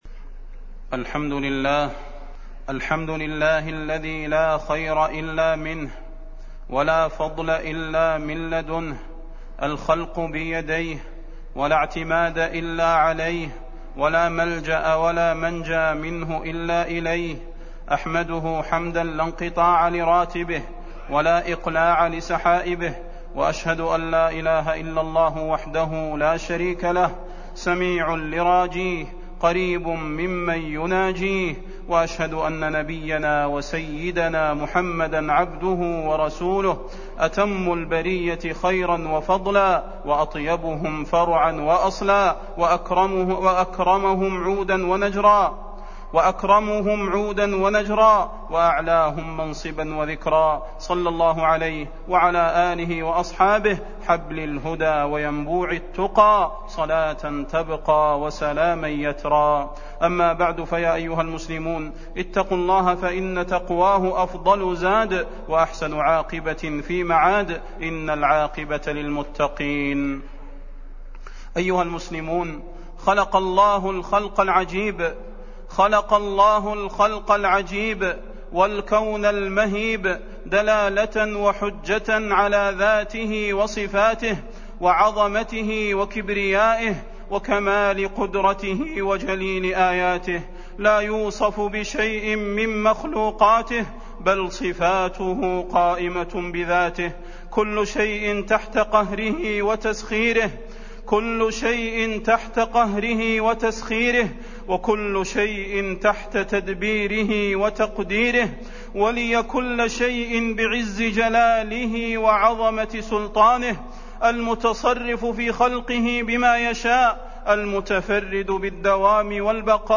تاريخ النشر ٢٥ ربيع الأول ١٤٢٨ هـ المكان: المسجد النبوي الشيخ: فضيلة الشيخ د. صلاح بن محمد البدير فضيلة الشيخ د. صلاح بن محمد البدير خلق الله